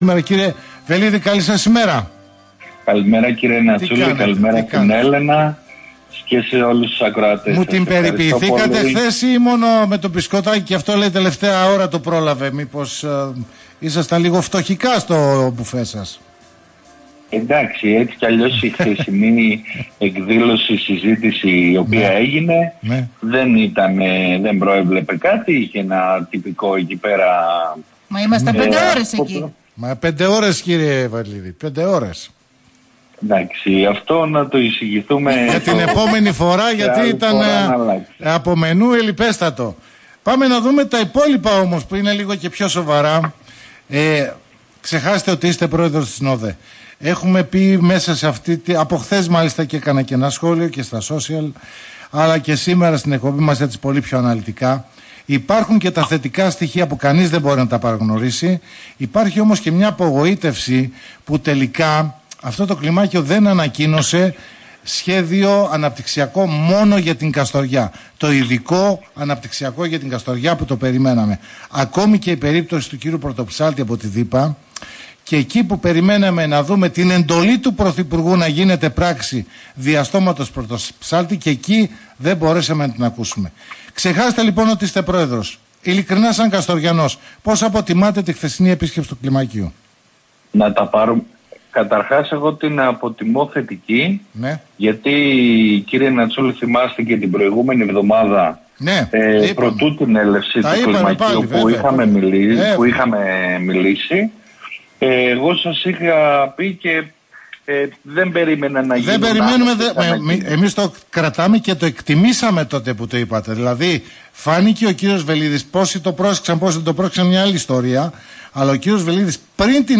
σύσκεψη με τοπικά στελέχη της ΝΔ και τοποθέτηση Ζ. Τζηκαλάγια στη συνάντηση (συνέντευξη)